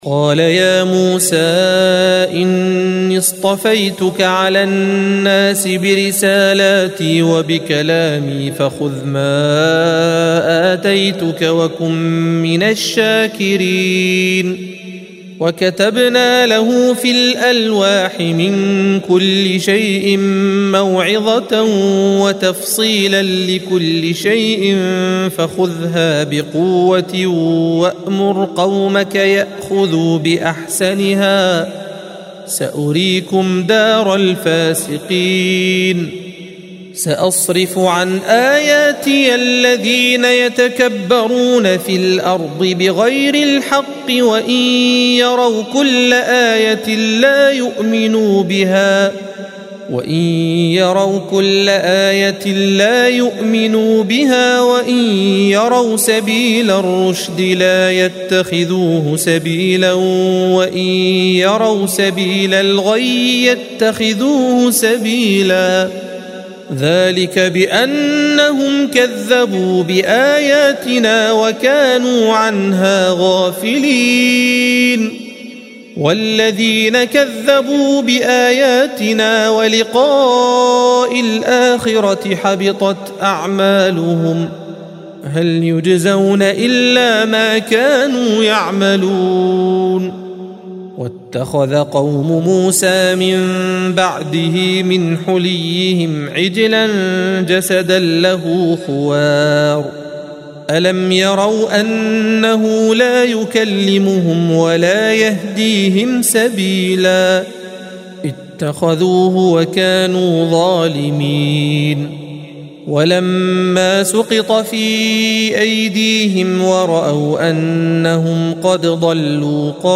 الصفحة 168 - القارئ